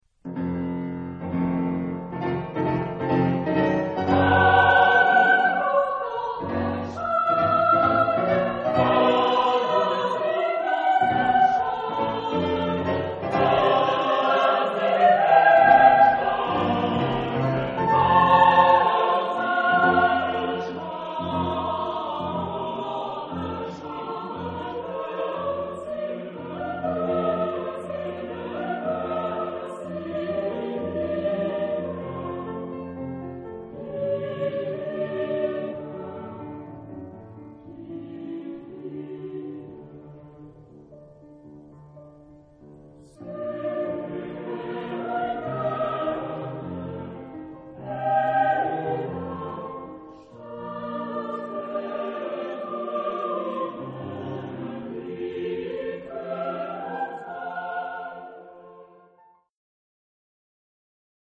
Género/Estilo/Forma: Profano ; Romántico ; Lied
Tipo de formación coral: SATB  (4 voces Coro mixto )
Instrumentos: Piano (1)
Tonalidad : si bemol mayor